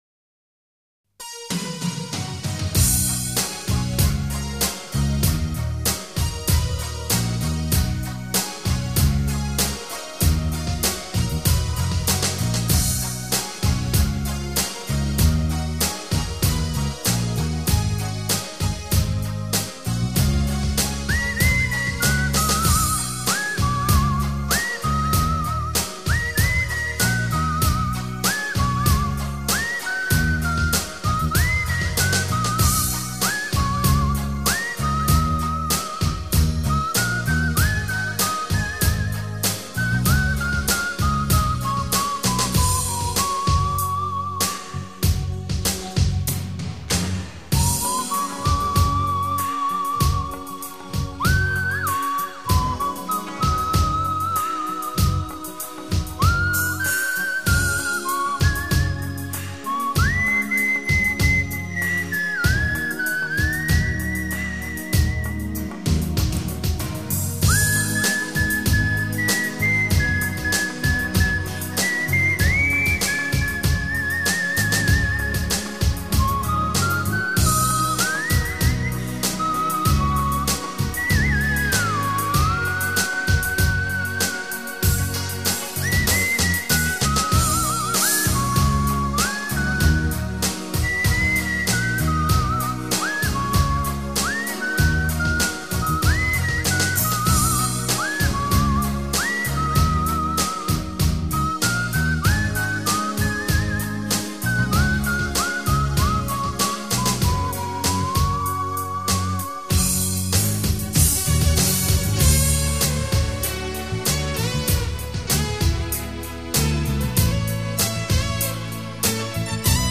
音乐风格: New Age / Electronic